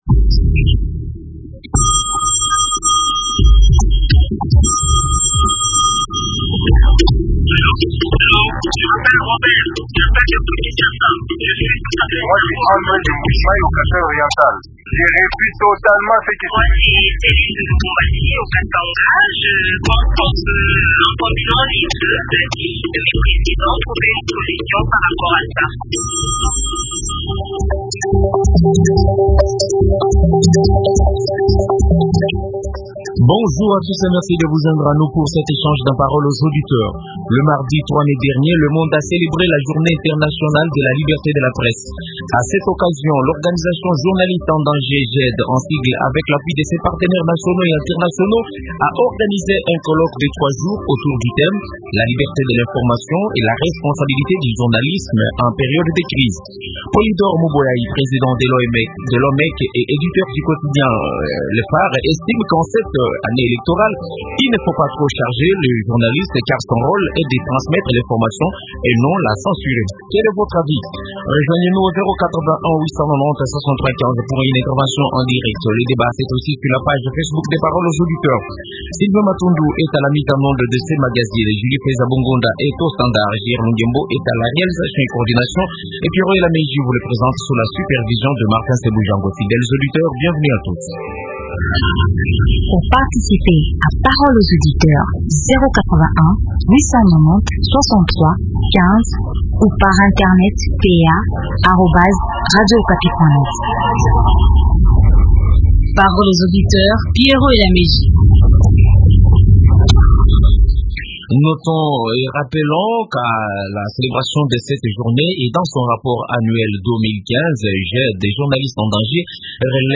Invité